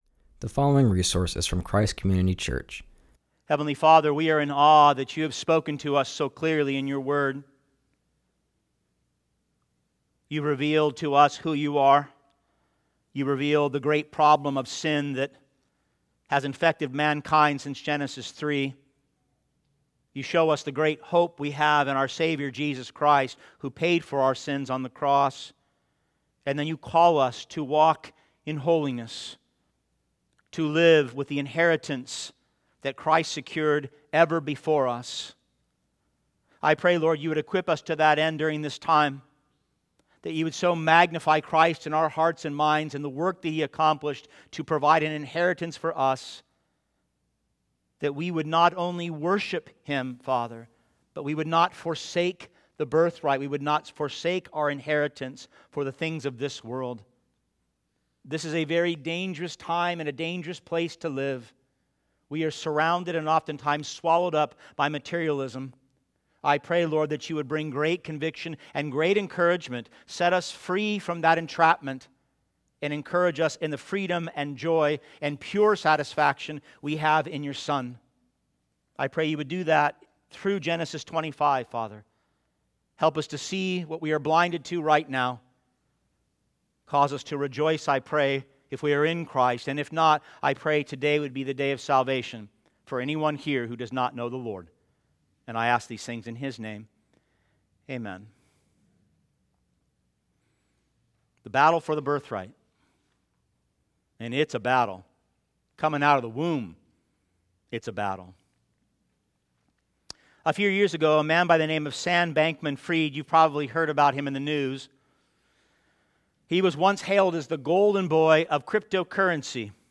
preaches from Genesis 25